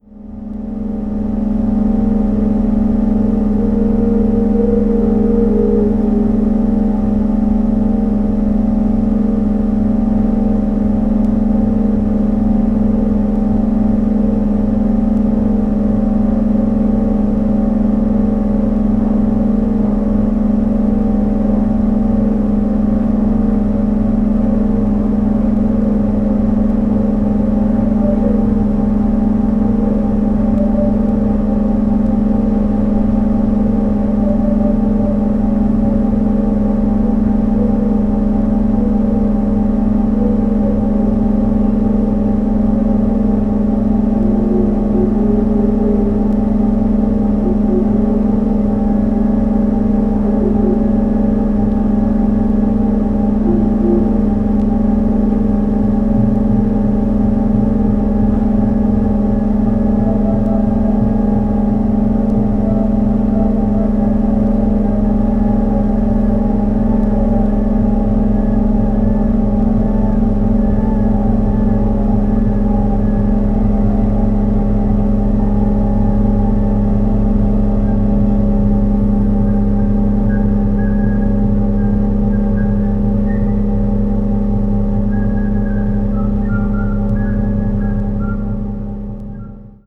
ambient   collage   drone   experimental   modern classical